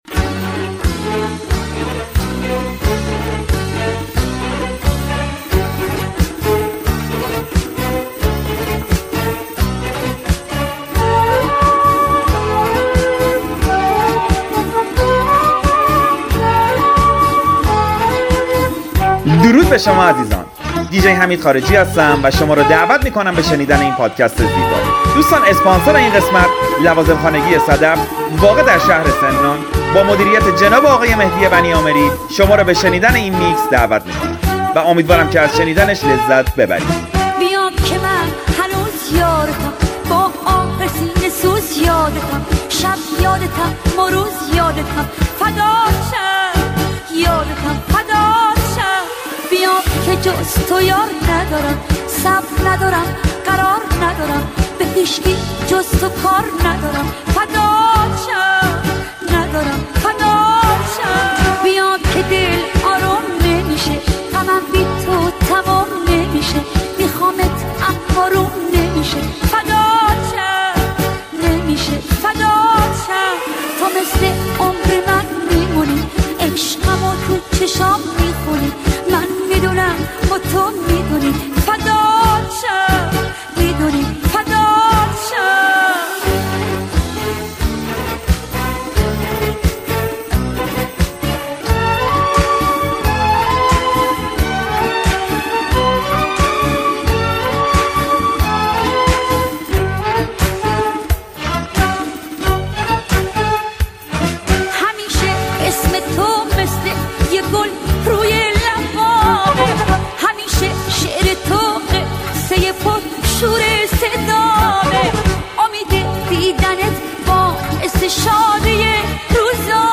موزیک ریمیکس